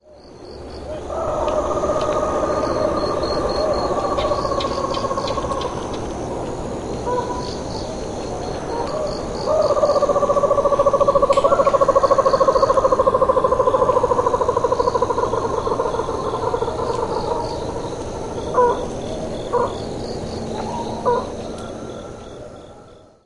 臺灣繁殖鳥類大調查 - 灰腳秧雞
Rallina eurizonoides 灰腳秧雞 別名： 灰腳斑秧雞 學名： Rallina eurizonoides, 臺灣特有亞種(R. e. formosana)